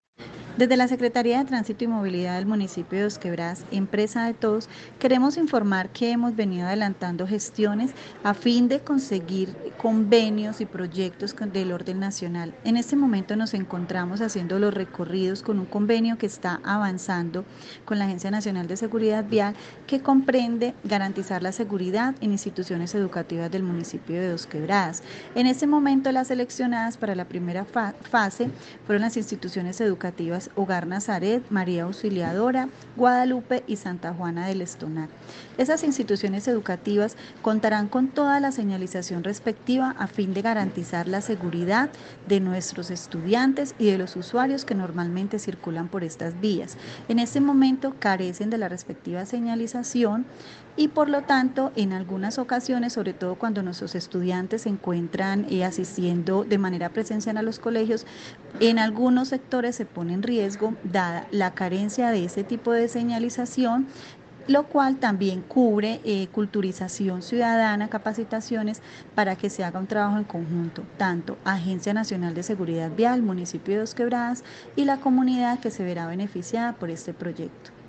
Comunicado-311-Audi12-Secretaria-de-Transito-Movilidad-Angela-Jazmin-Hidalgo.mp3